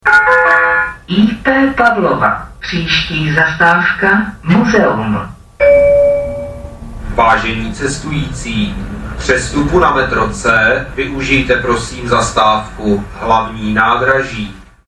- Hlášení o přestupu na metro až v zastávce Hlavní nádraží si